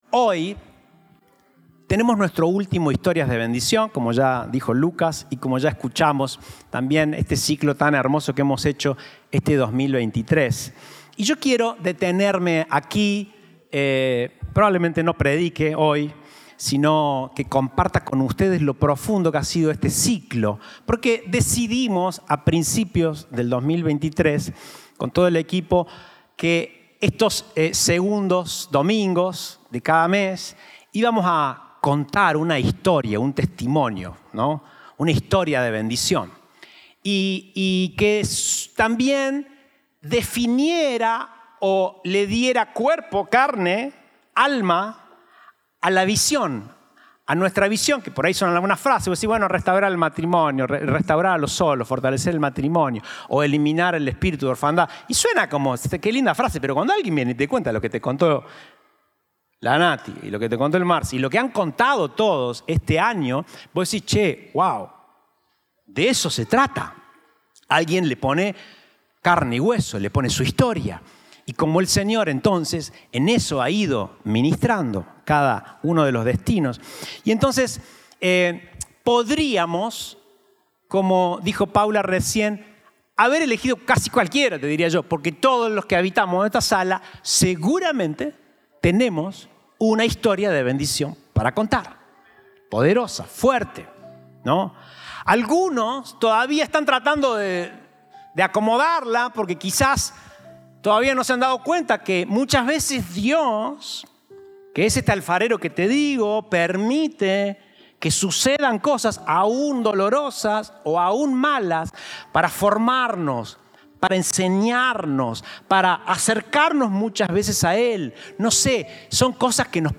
Compartimos el mensaje del Domingo 10 de Diciembre de 2023